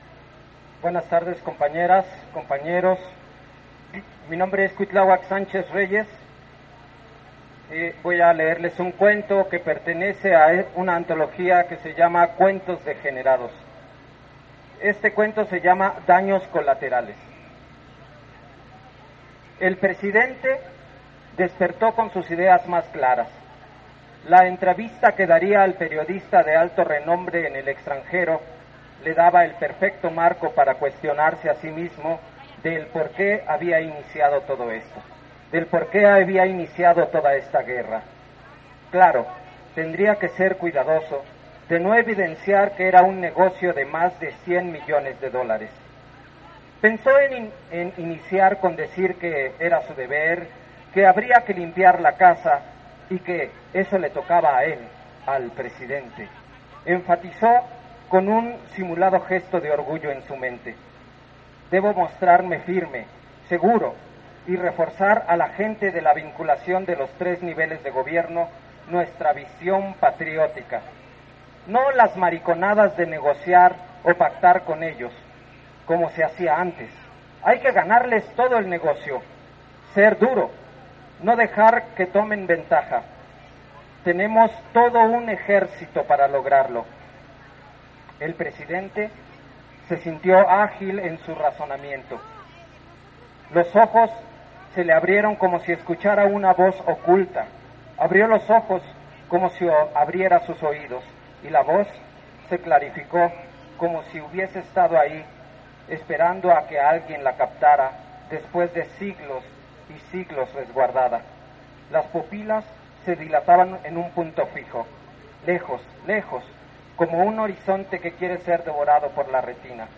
El pasado 25 de noviembre se realizó una marcha por el 25 de noviembre "día internacional contra la violencia a la Mujer"que partió del monumento a la Madre hacia el Palacio de Bellas Artes, la cual inició a medio día, esta fue convocada por colectivos feministas, como Pan y Rosas, Mujeres y la Sexta, entre otras; apróximadamente a las 2 de la tarde arribaron a la av. Juarez donde se llevó acabo la actividad político-cultural.